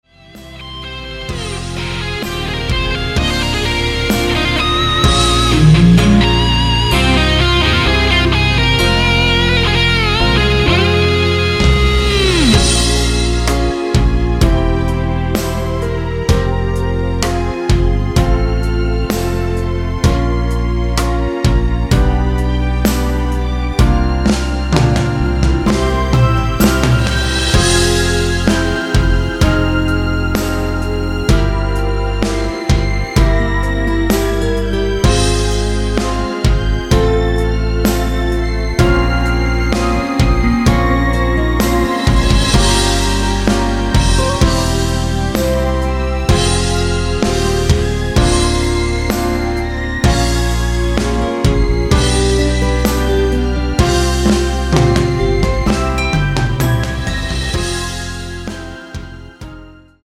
발매일 2000.01. 여성분이 부르실수 있는 MR 입니다.(미리듣기 참조)
Fm
앞부분30초, 뒷부분30초씩 편집해서 올려 드리고 있습니다.
중간에 음이 끈어지고 다시 나오는 이유는